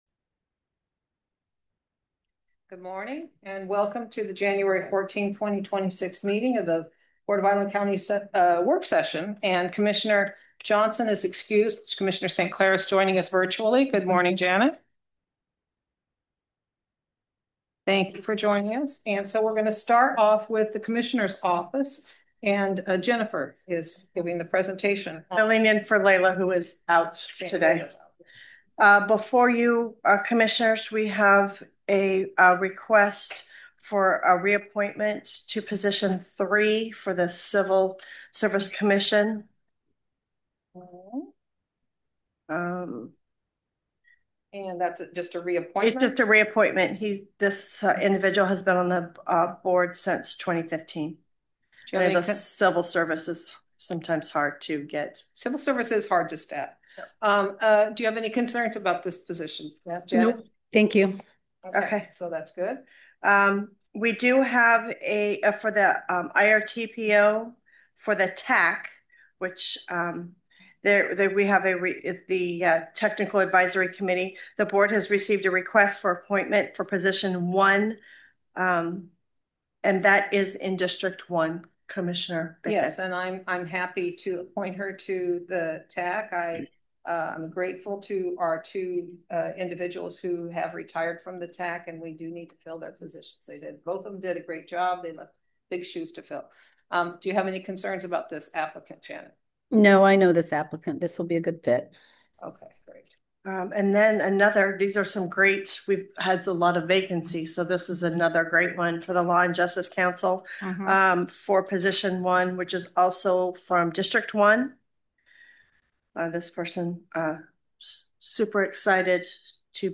BOCC Workshop 1/14/26 audio